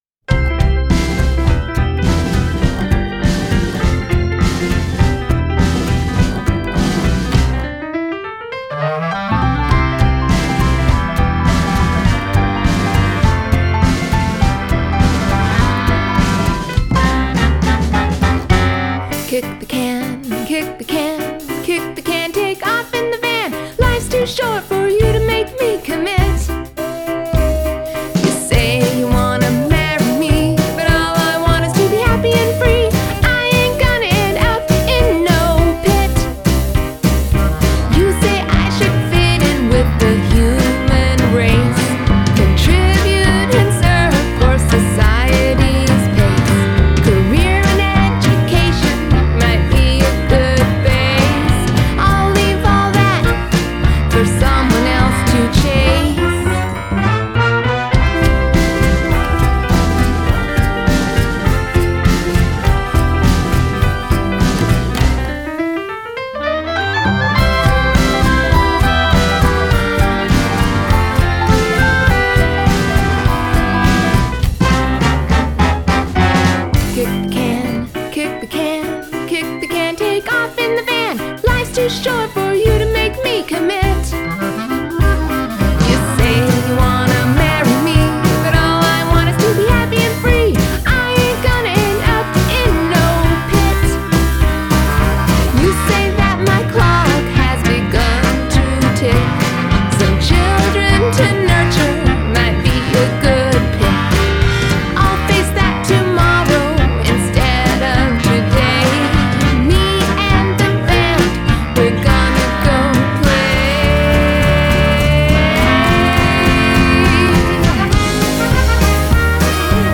Adult Contemporary
Indie Pop , Musical Theatre